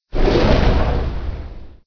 missile_torpedo.mp3